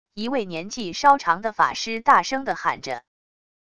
一位年纪稍长的法师大声的喊着wav音频